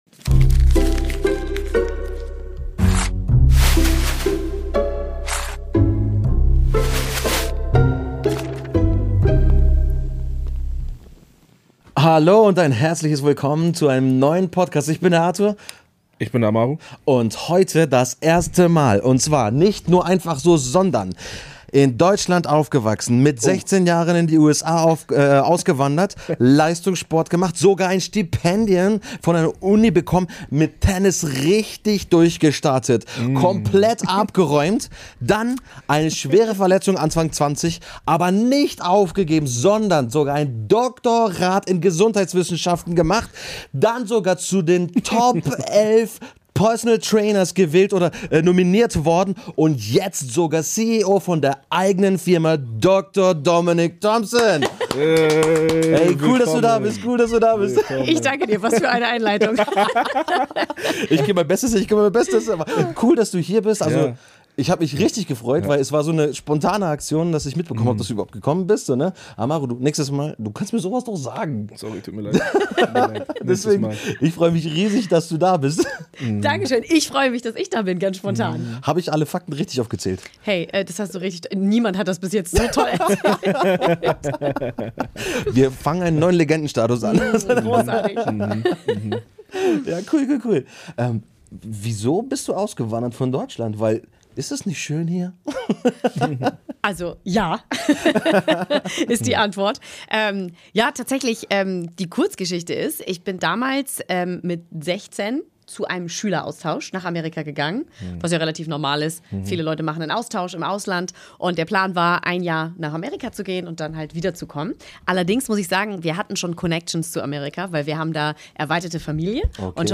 Wir sprechen über Entscheidungen, persönliche Herausforderungen und darüber, wie man gesunde Wege findet, mit Druck, Erwartungen und Veränderungen im Leben umzugehen. Ein ehrliches Gespräch über Orientierung, innere Stärke und mentale Klarheit.